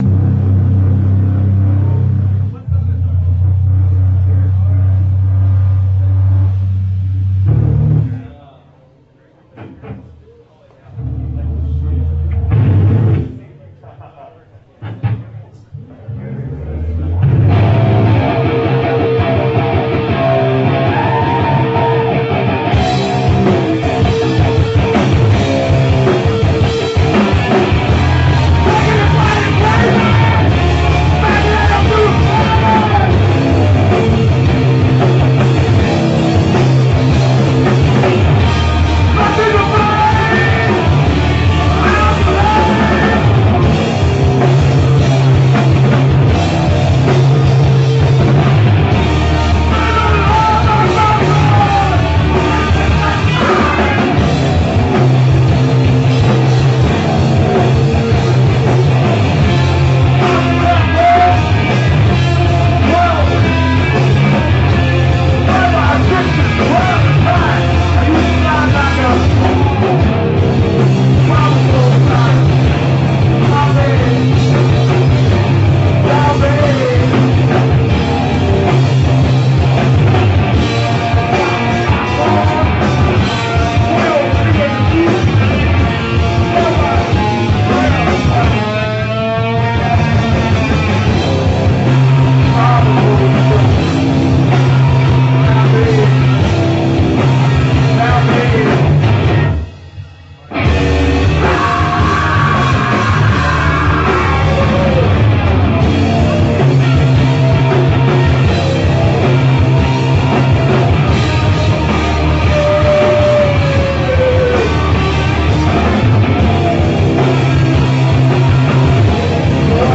PRESENTS LIVE ON TAPE ROCK VIDEO FROM